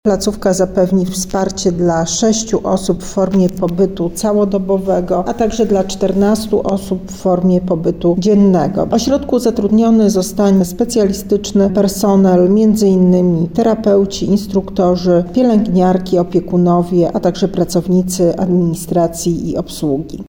-mówi